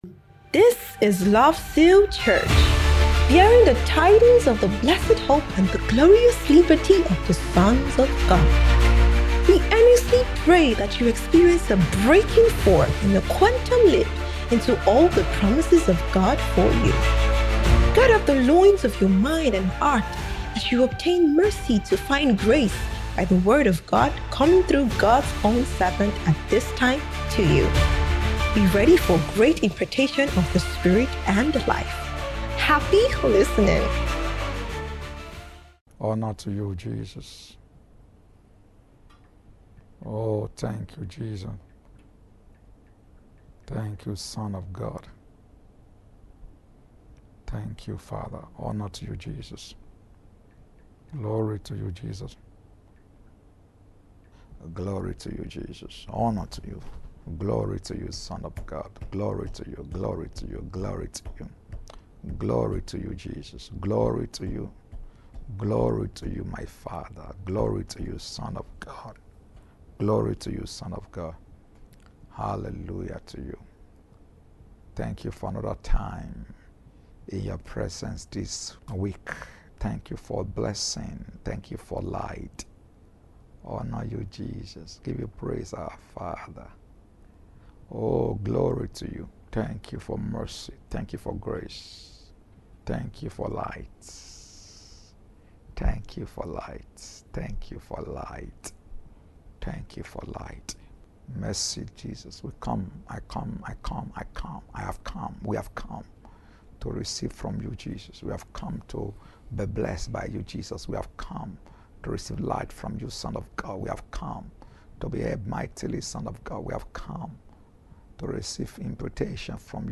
SOBT - SPECIAL MIDWEEK TEACHING SERIES